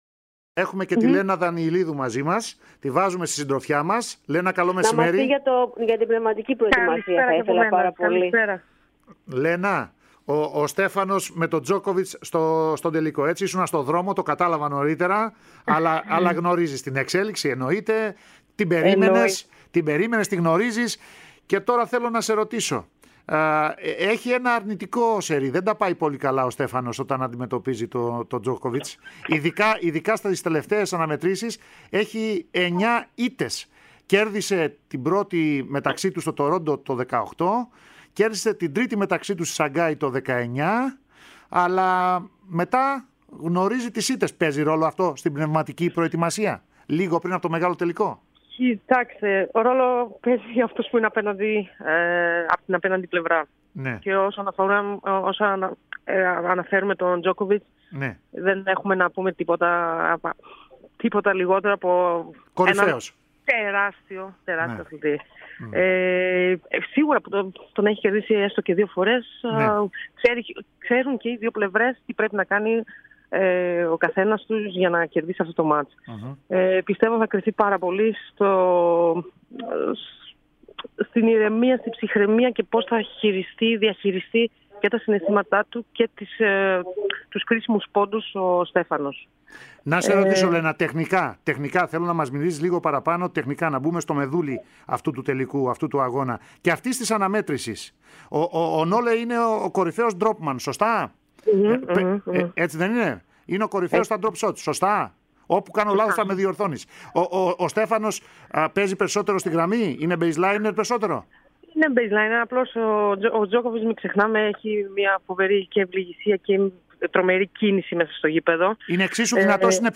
Δανιηλίδου στην ΕΡΑ ΣΠΟΡ: «Ελπίζω να κατακτήσει τον τίτλο ο Τσιτσιπάς» (audio)